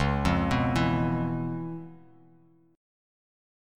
Db7#9 Chord
Listen to Db7#9 strummed